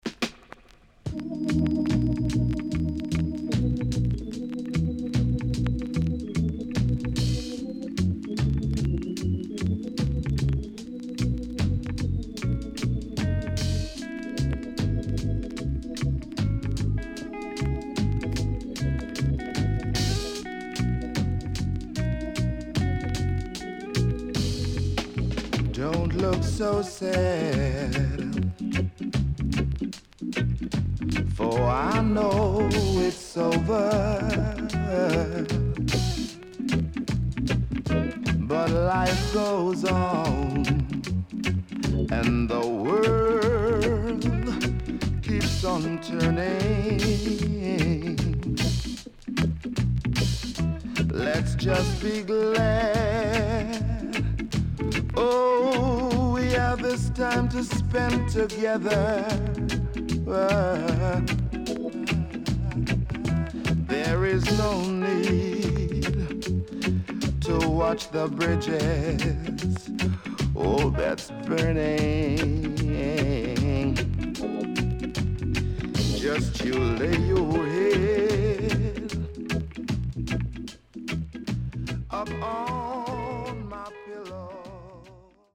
EARLY REGGAE
SIDE A:序盤ノイズ目立ちますが徐々に落ち着きます。全体的にチリノイズがあり、所々プチノイズ入ります。